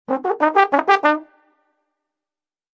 Descarga de Sonidos mp3 Gratis: trompeta 5.